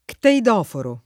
cteidoforo [ kteid 0 foro ] s. m. (zool.)